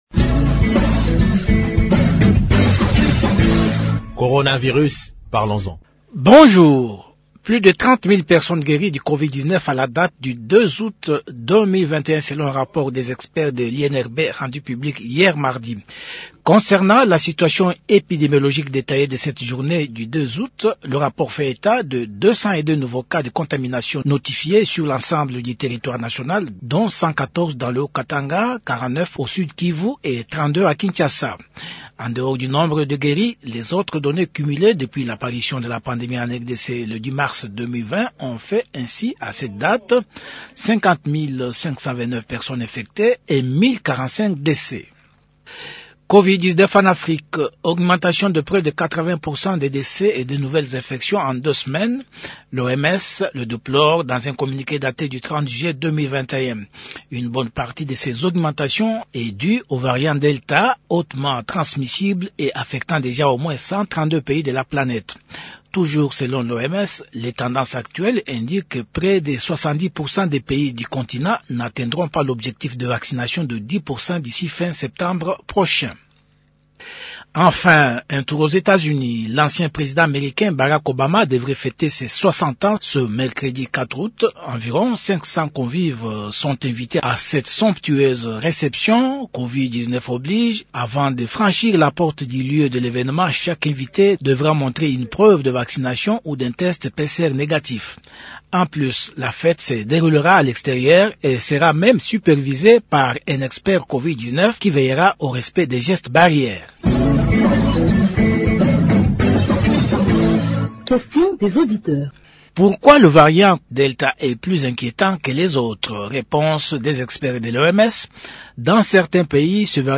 Actualité